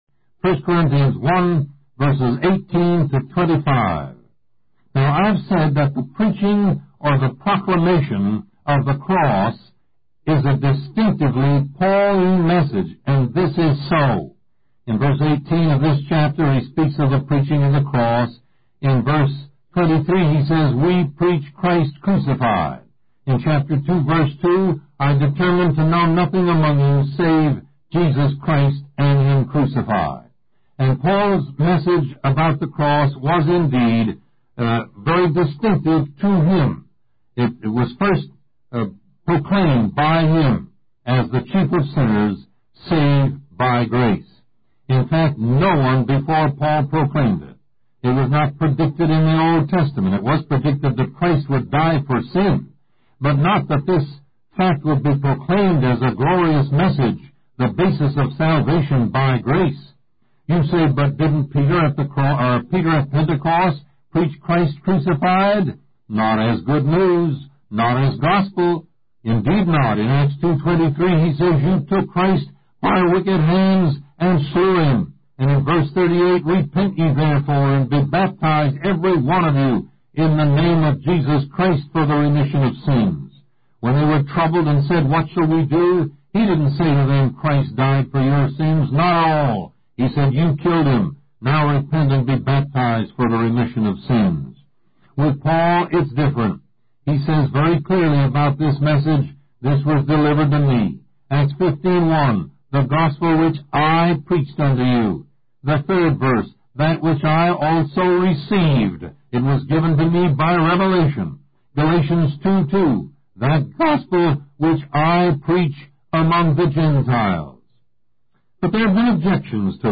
Lesson 8: Paul and the Cross